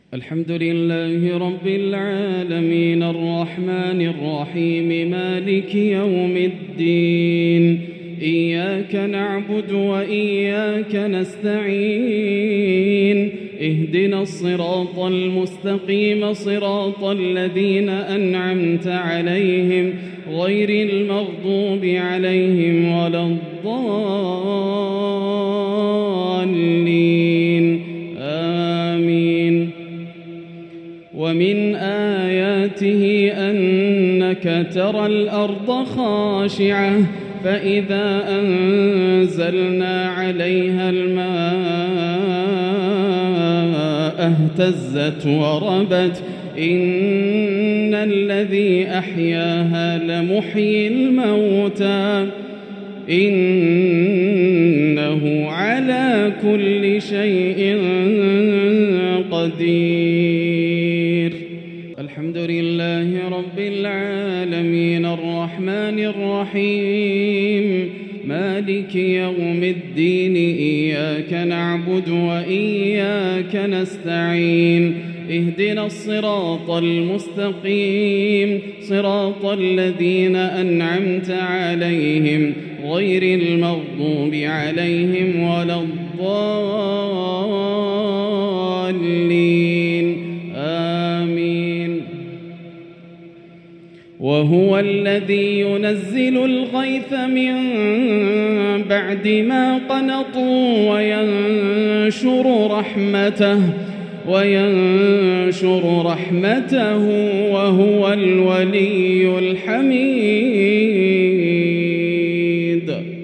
تلاوة من سورتي فصلت (39) والشورى (28) | مغرب الثلاثاء 6-2-1445هـ > عام 1445 > الفروض - تلاوات ياسر الدوسري